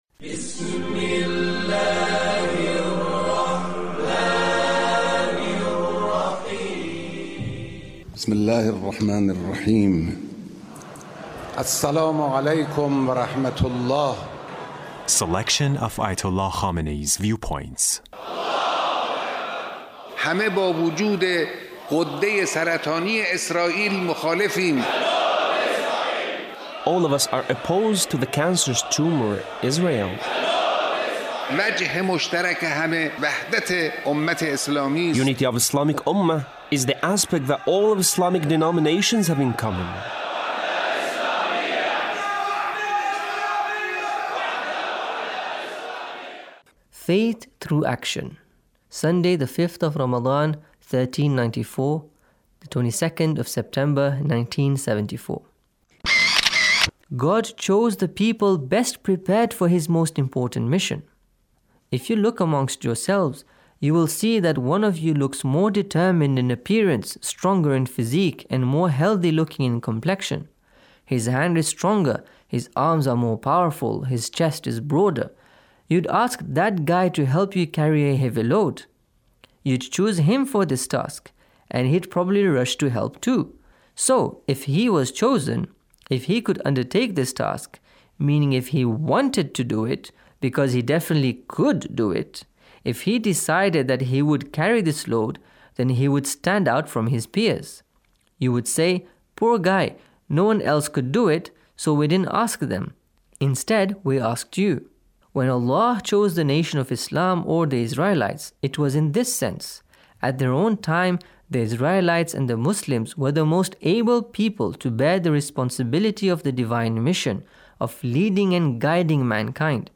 Leader's Speech (1564)